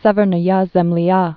(sĕvər-nə-yä zĕmlē, -lyä, syĭ-vyĭr-)